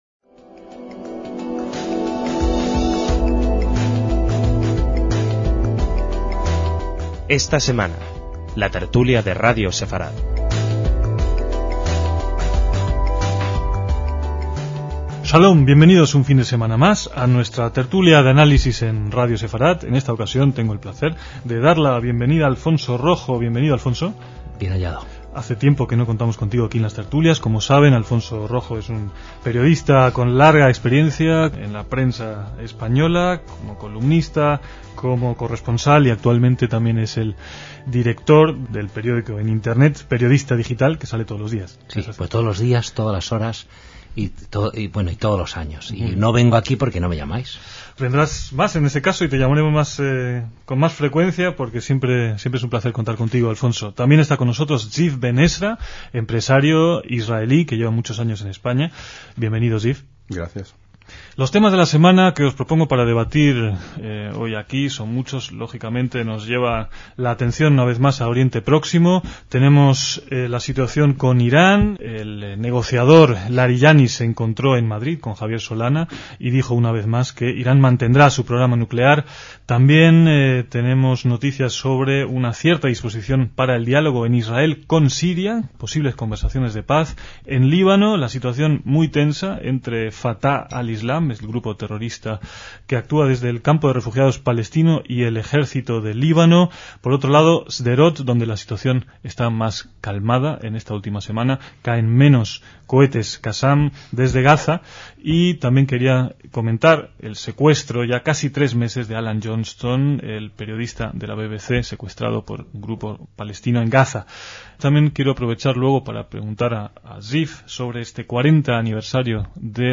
DECÍAMOS AYER (2/6/2007) - En esta tertulia radiofónica los temas que se abordaron fueron el programa nuclear de Irán, Siria, Líbano o el 40º aniversario de la Guerra de los Seis Días en 2007.